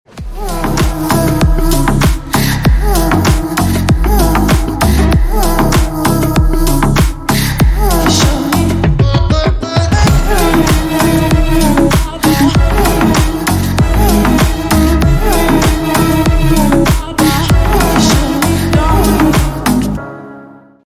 • Качество: 128, Stereo
восточные мотивы
house
Восточный клубный звонок